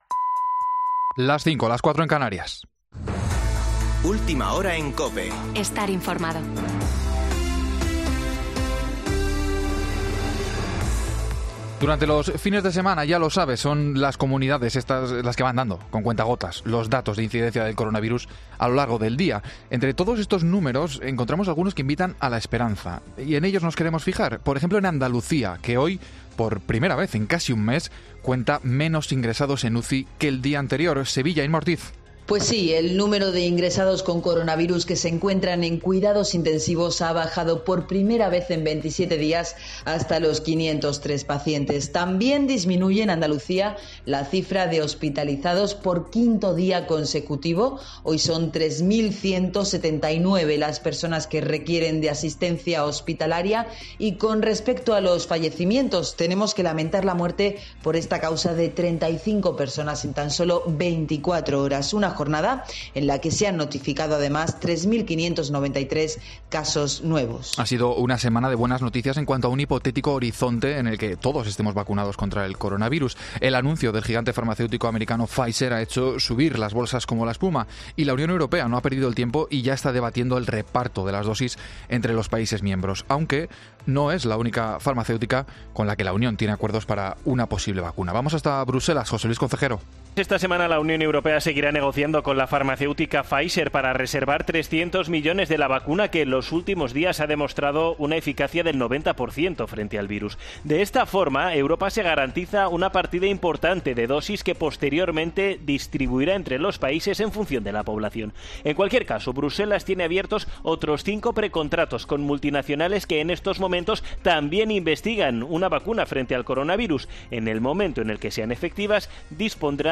Boletín de noticias de COPE del 15 de noviembre de 2020 a las 17.00 horas